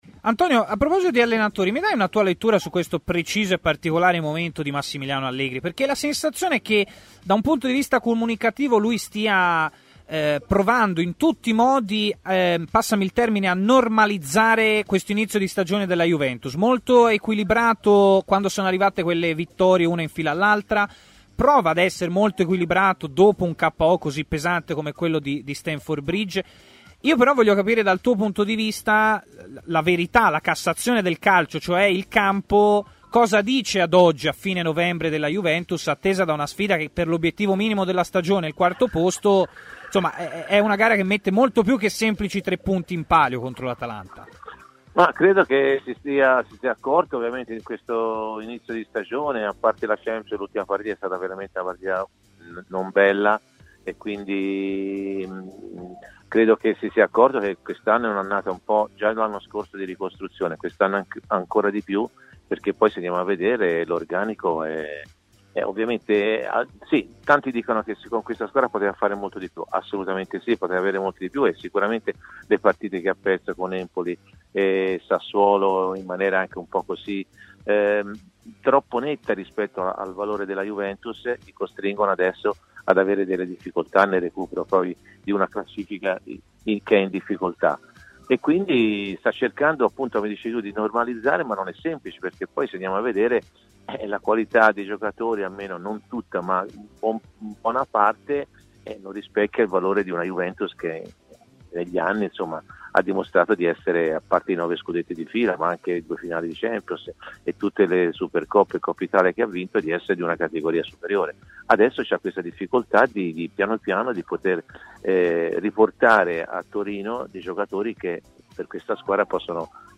Antonio Di Gennaro è intervenuto a Stadio Aperto, trasmissione pomeridiana di TMW Radio.